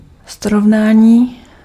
Ääntäminen
IPA: [kɔ̃.pa.ʁɛ.zɔ̃]